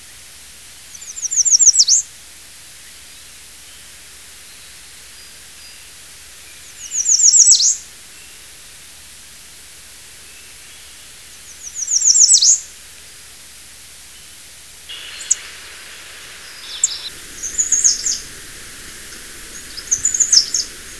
American Redstart
Setophaga ruticilla
VOICE: The call is a sharp "chip" note, which it gives often as it forages; usually sings on the winter grounds only just before departure in April.